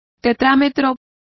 Also find out how tetrametros is pronounced correctly.